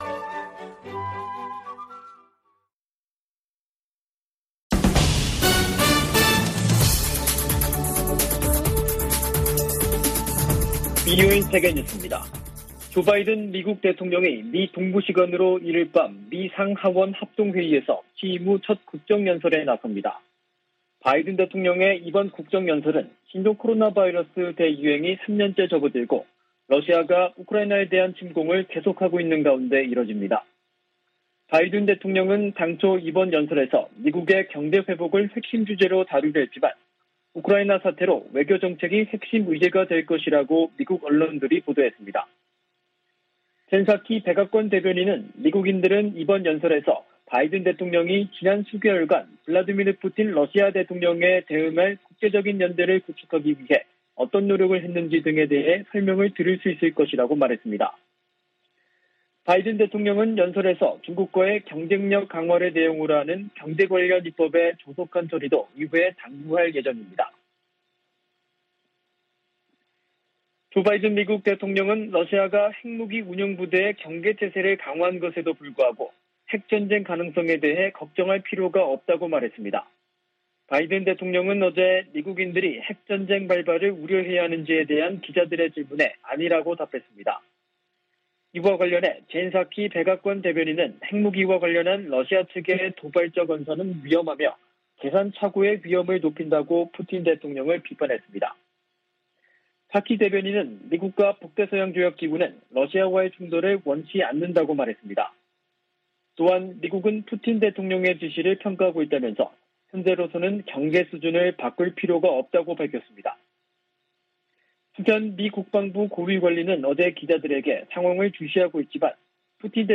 VOA 한국어 간판 뉴스 프로그램 '뉴스 투데이', 2022년 3월 1일 2부 방송입니다. 미국, 영국, 일본 등 유엔 안보리 11개국이 북한 탄도미사일 발사를 규탄하고 단호한 제재 이행 방침을 확인했습니다. 괌 당국은 북한의 탄도미사일 시험 발사 재개와 관련해 모든 움직임을 계속 감시하고 있다고 밝혔습니다. 미국의 전문가들은 북한의 최근 미사일 발사가 우주개발을 가장한 탄도미사일 시험일 뿐이라고 지적하고 있습니다.